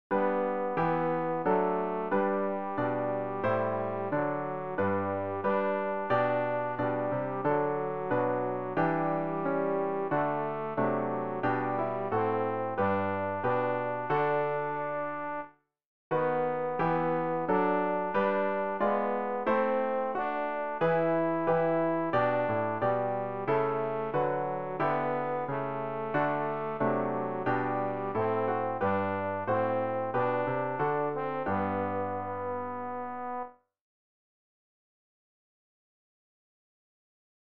tenor rg-441-o-welt-sieh-hier-dein-leben.mp3